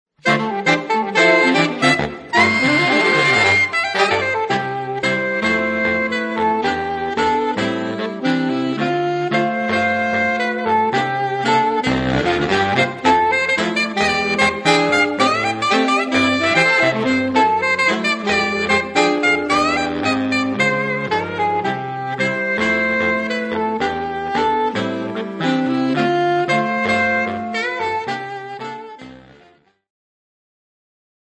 4 - 5 Saxophone
Der heitere und ausdrucksvolle Pop-Jazz-Song